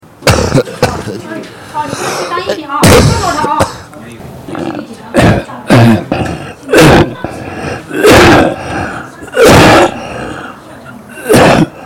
咳声低微.mp3